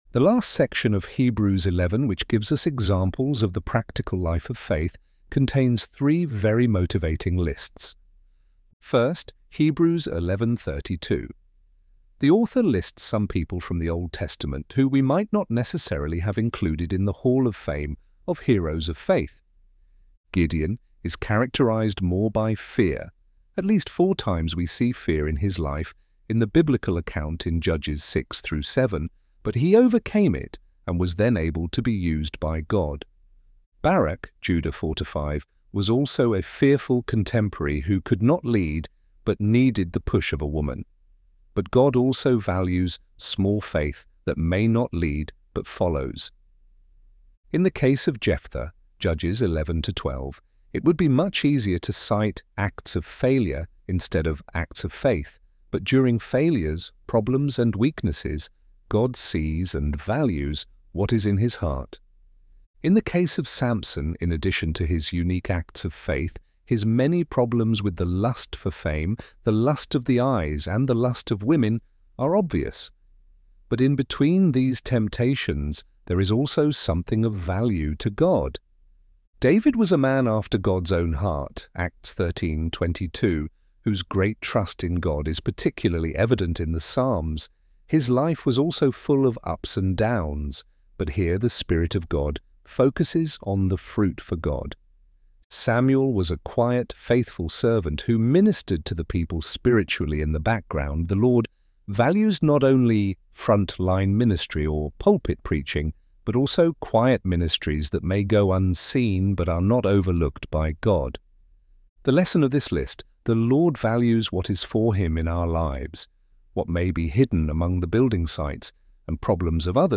ElevenLabs_Three_Encouraging_Lists.mp3